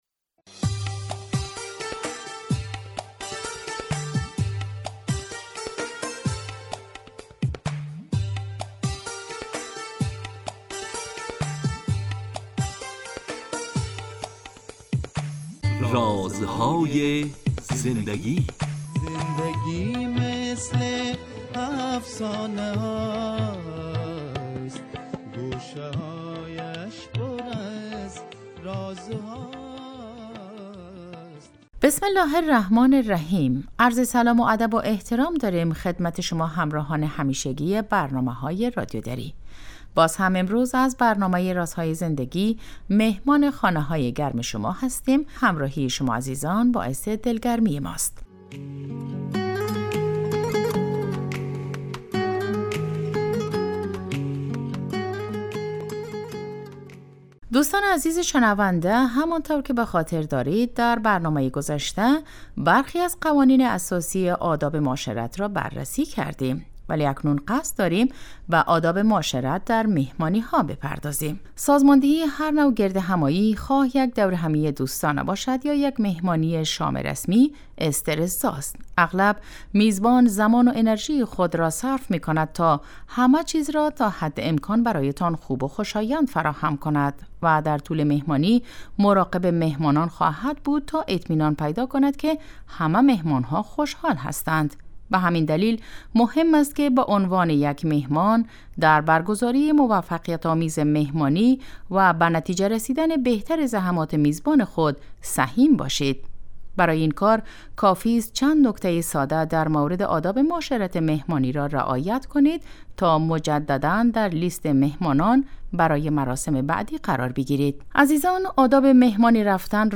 گوینده سرکار خانم